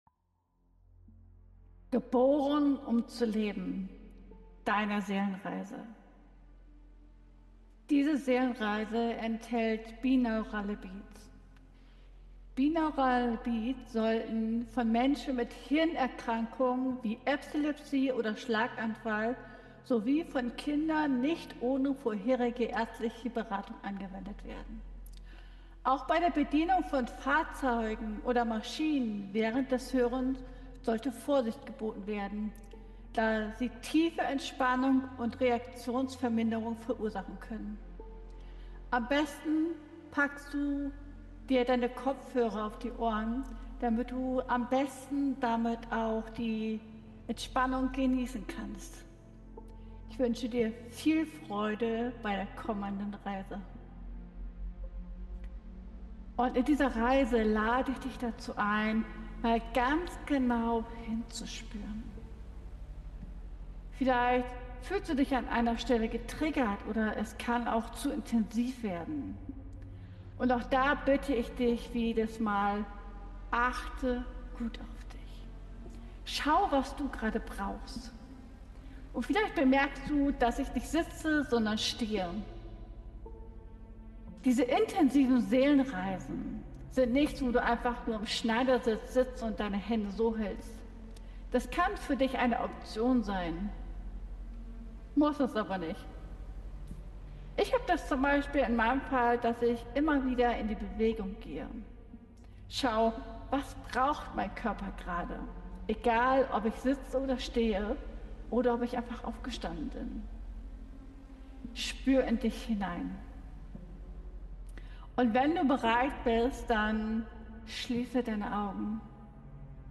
Geboren, um zu leben – Deine Seelenreise zur inneren Kraft mit Binauralen Beats ~ Ankommen lassen Podcast